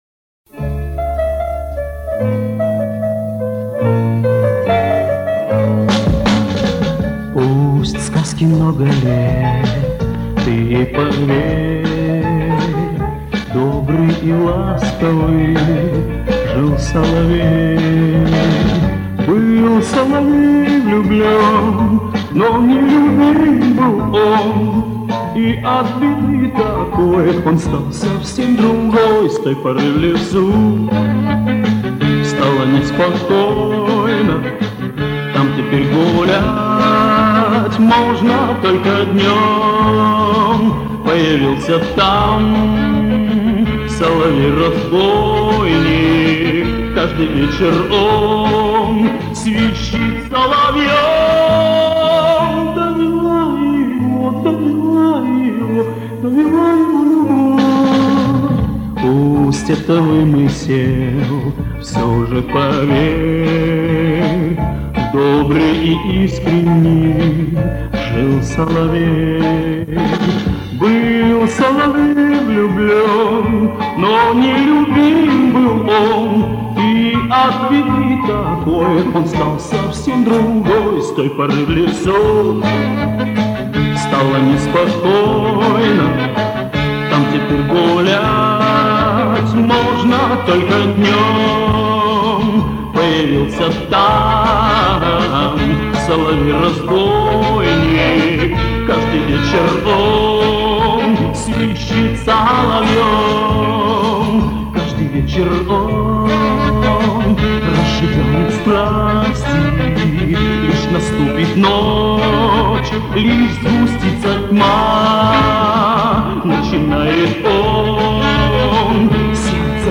поет под большой оркестр
Я убрал щелчки и подкорректировал уровень.